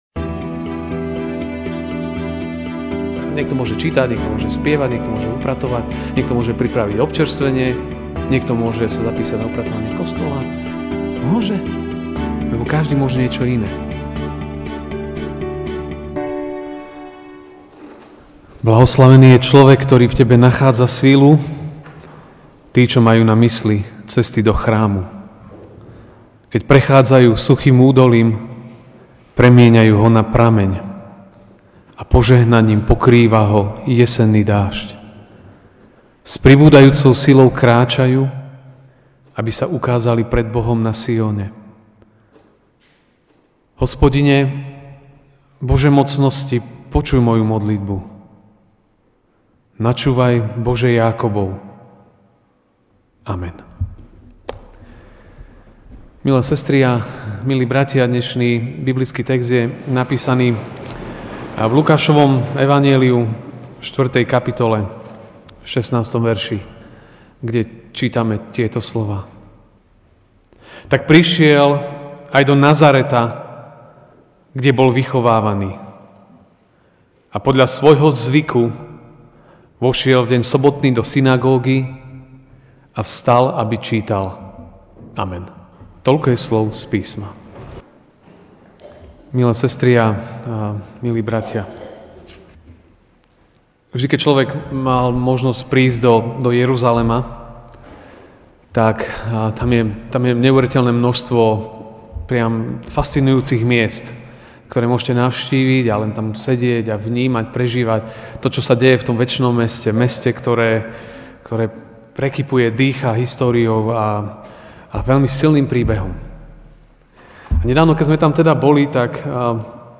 Ranná kázeň: Dobré obyčaje sú požehnaním (L 4, 16)Tak prišiel aj do Nazareta, kde bol vychovaný, a podľa svojho zvyku vošiel v deň sobotný do synagógy a vstal, aby čítal.